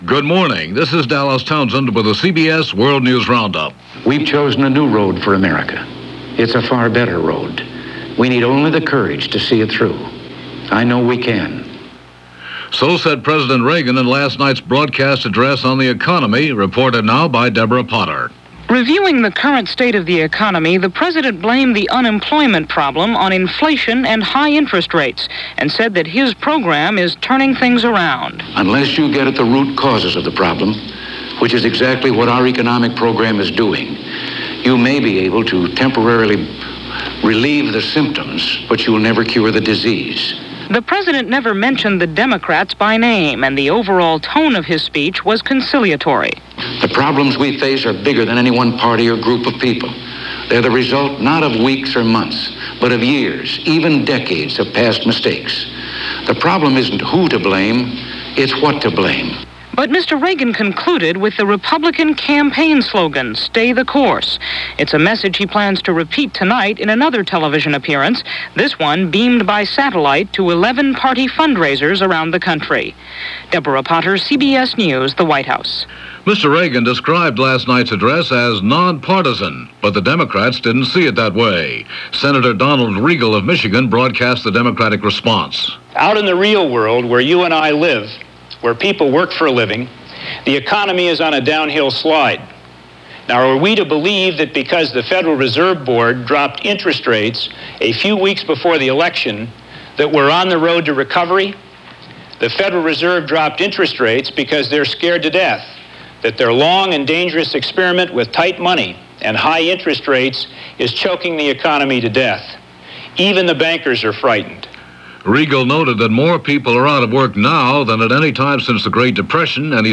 CBS World News Roundup – Newsbreak – 9am News